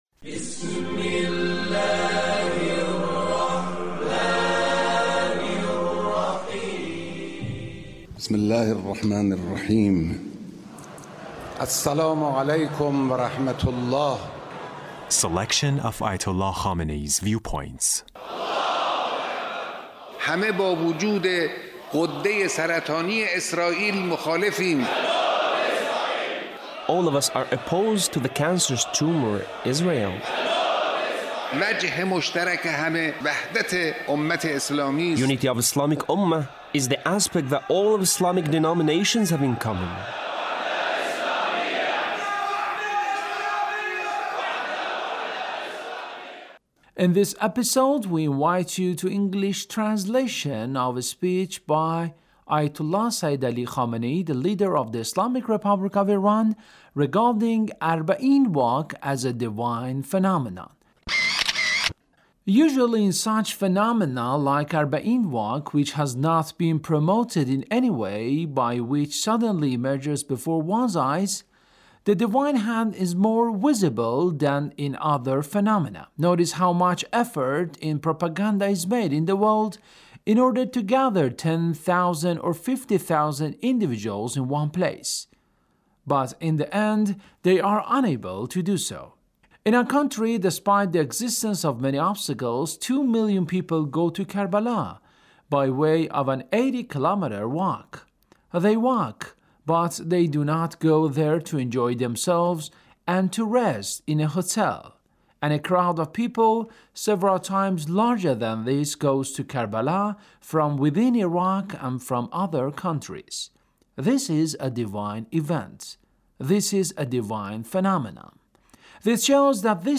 Leader's Speech On Arbaeen